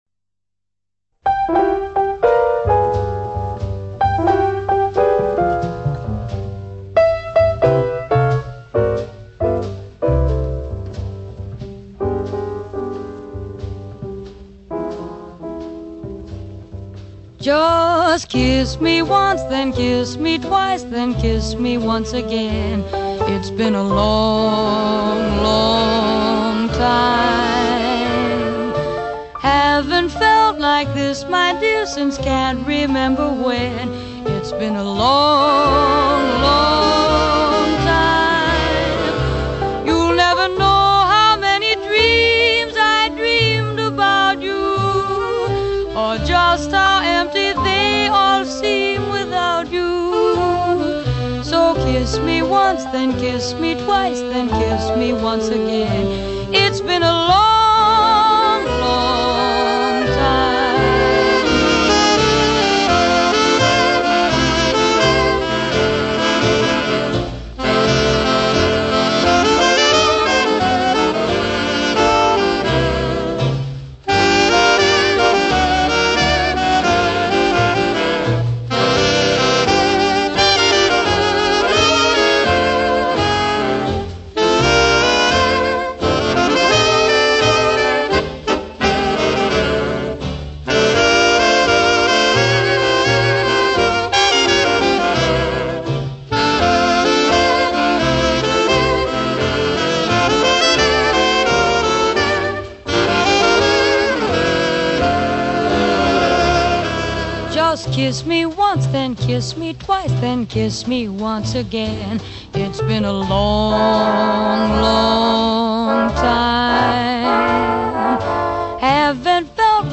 Voicing: Big Band with Vocal